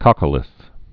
(kŏkə-lĭth)